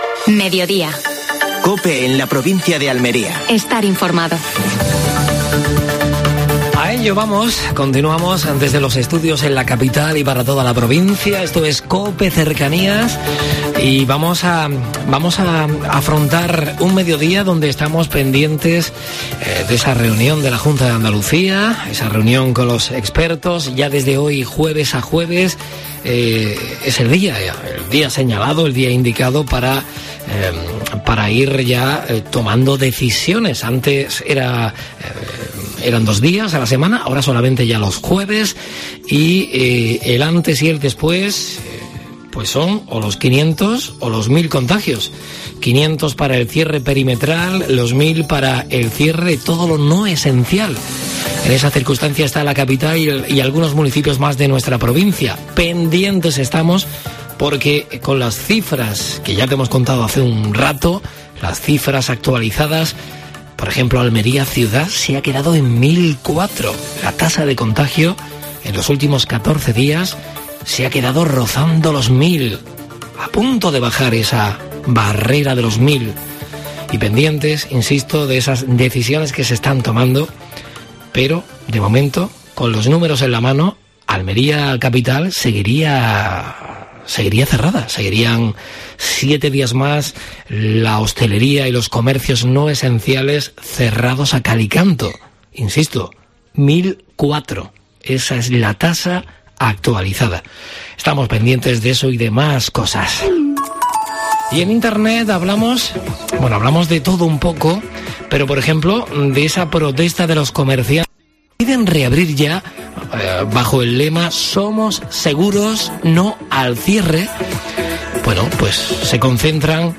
Última hora deportiva.